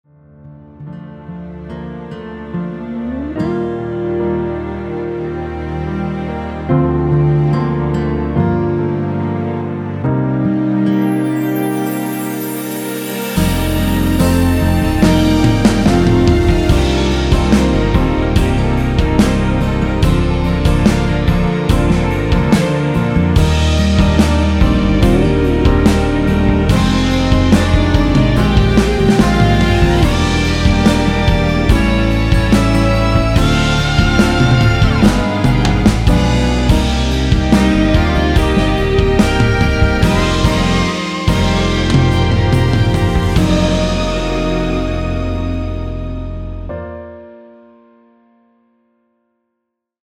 1절 + 후렴 편곡 MR 입니다.
원키에서(-2)내린 1절후 후렴으로 진행되게 편곡한 MR 입니다.(미리듣기및 가사 참조)
노래가 바로 시작 하는 곡이라 전주 만들어 놓았으며
Bb
앞부분30초, 뒷부분30초씩 편집해서 올려 드리고 있습니다.
중간에 음이 끈어지고 다시 나오는 이유는